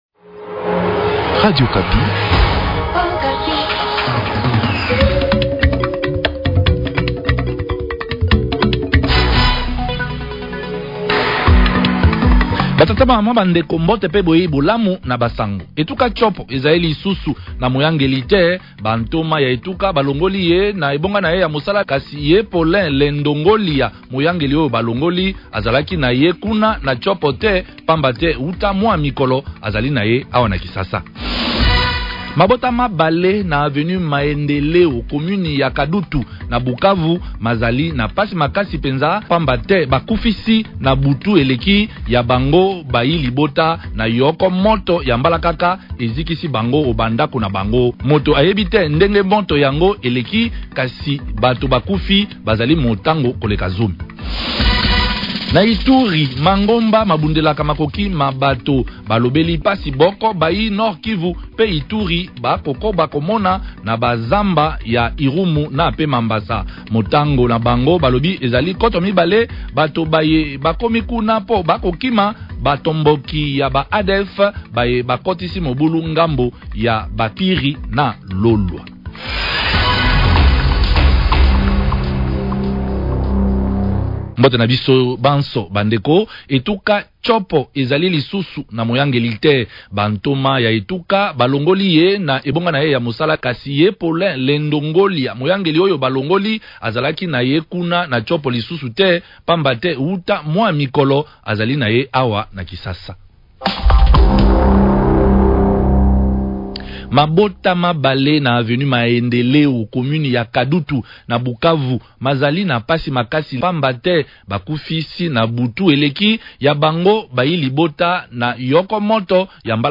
journal lingala